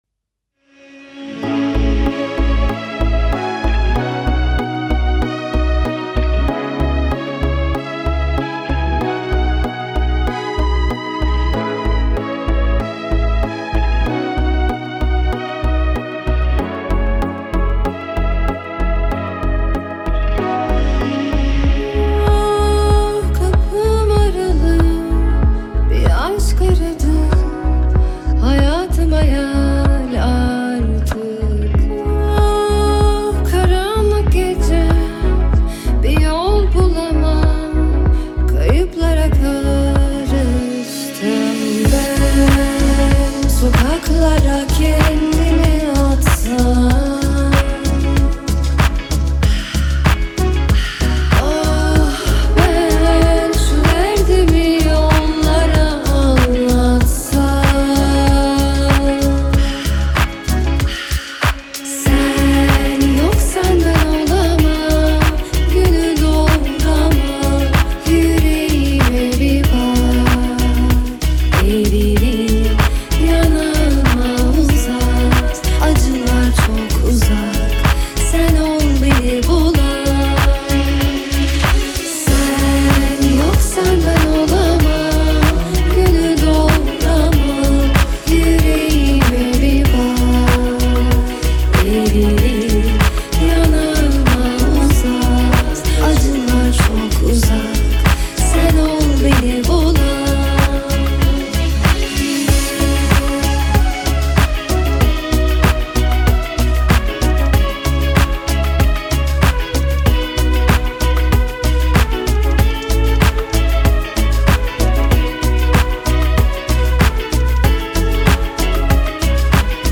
duygusal huzurlu rahatlatıcı şarkı.